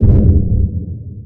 TheExperienceLight - A lightened version of the official VIP The Experience soundpack.
boomin.wav